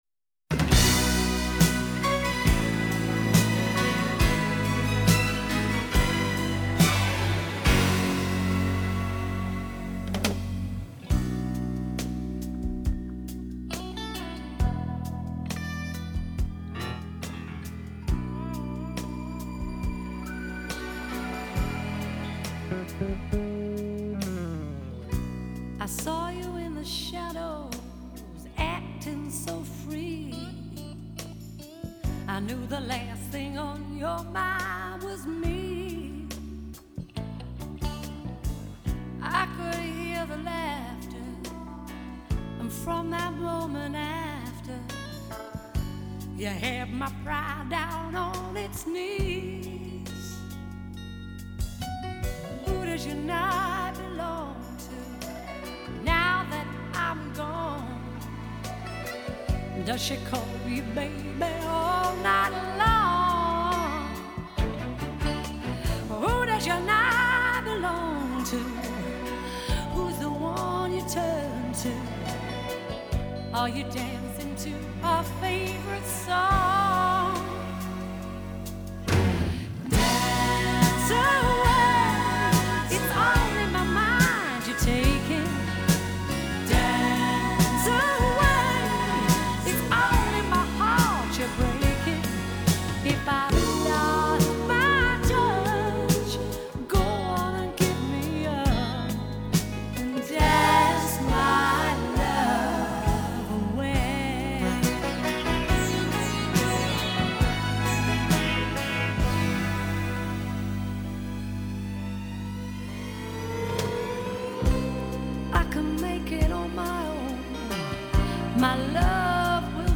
прекрасные и мелодичные песни!